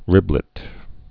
(rĭblĭt)